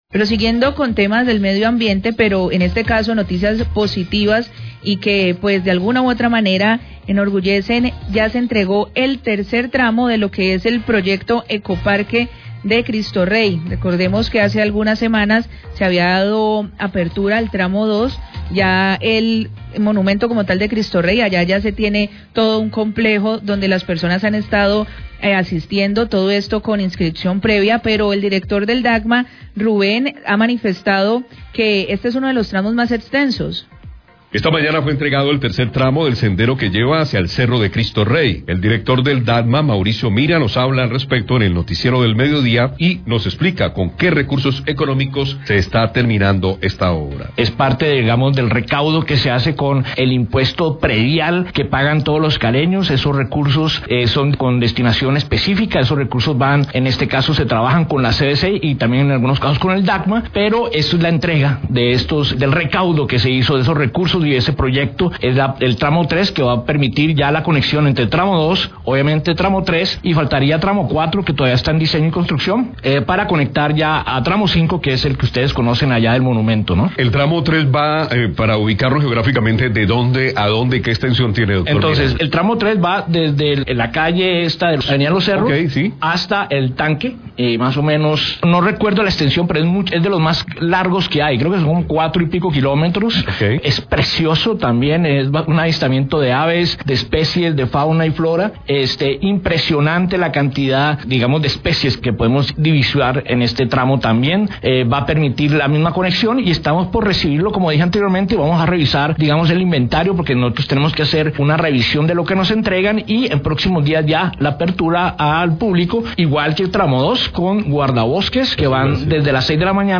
Radio
Director del DAGMA, Mauricio Mira, habló acerca de la entrega del tramo 3 del corredor ambiental a Cristo Rey y los recursos que fueron usados para su construcción.